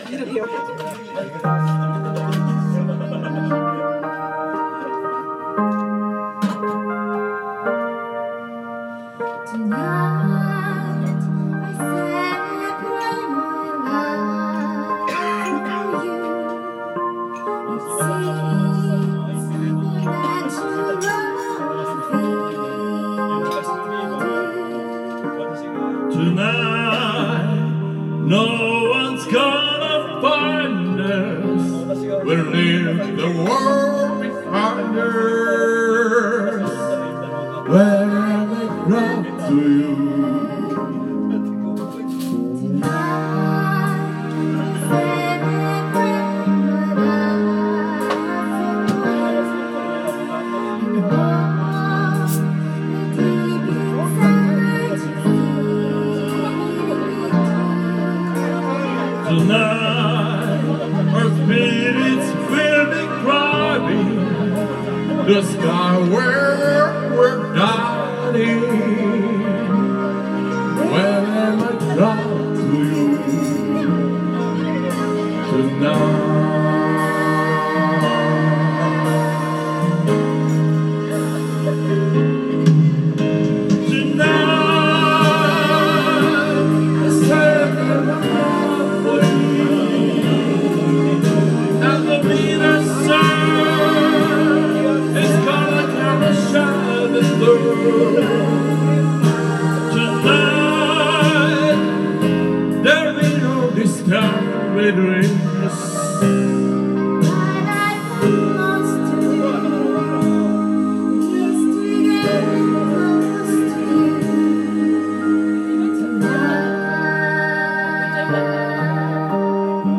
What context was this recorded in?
Duet & Chorus Night Vol. 13 TURN TABLE